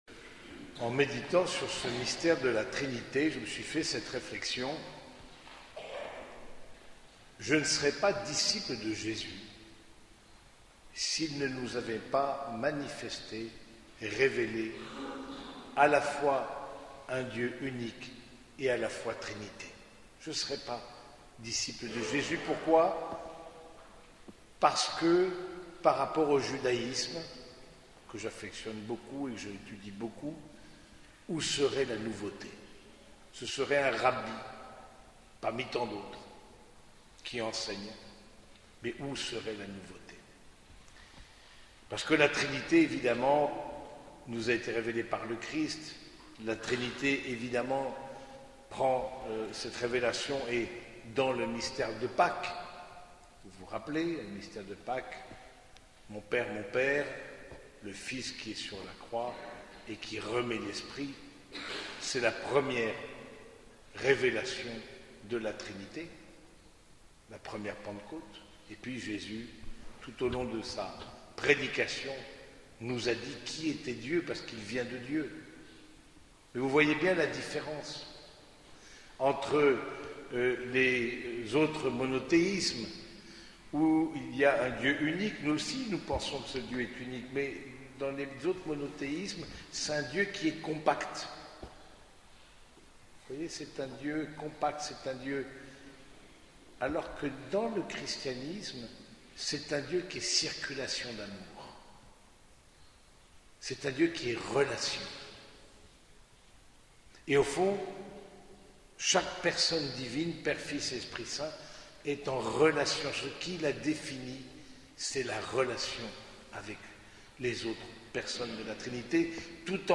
Cette homélie a été prononcée au cours de la messe dominicale à l’église Saint-Germain de Compiègne.